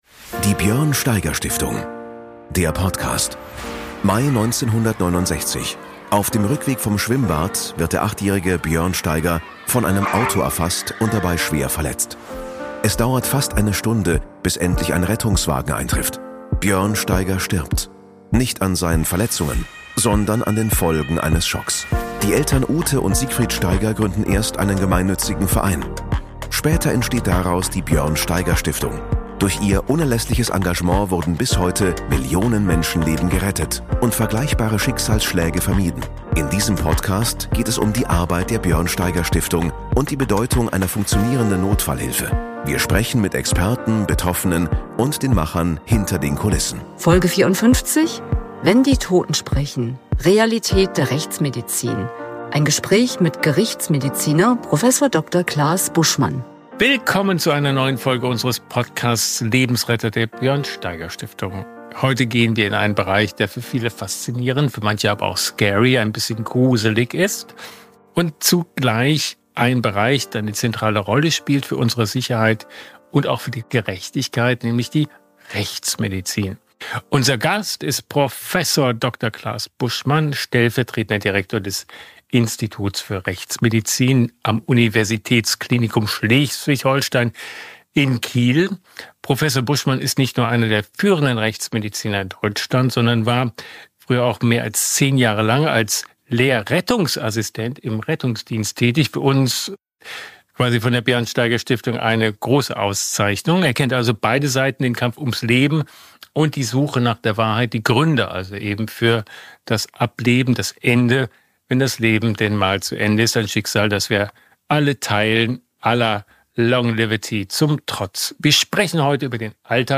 Béla Anda spricht mit ihm über spektakuläre und bewegende Fälle, über die enge Zusammenarbeit mit Polizei und Justiz, und darüber, was die Arbeit mit den Toten uns Lebenden lehren kann.